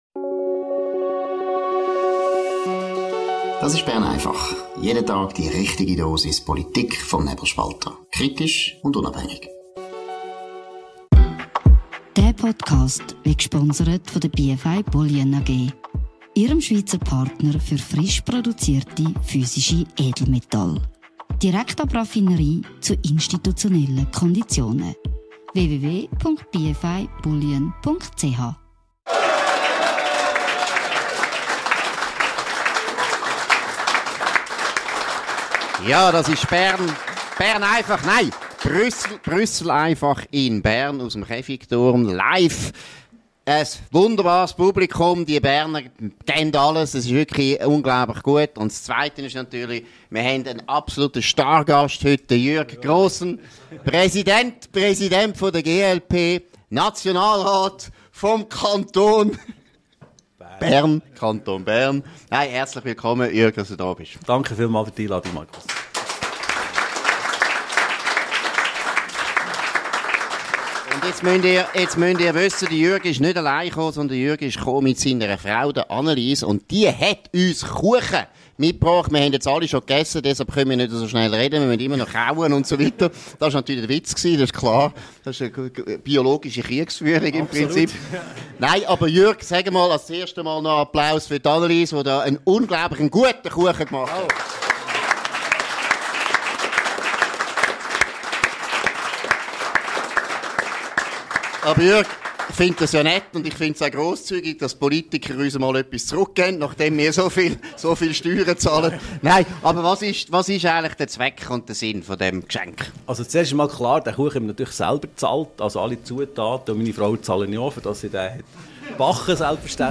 In der neuesten Ausgabe von «Brüssel einfach» ist Jürg Grossen, Präsident der Grünliberalen, zu Gast.